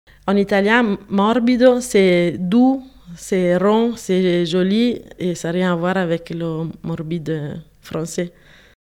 Morbido play all stop prononciation Morbido ↘ explication Morbido, en italien, c’est doux, c’est rond, c’est joli et ça n’a rien à voir avec le morbide en français.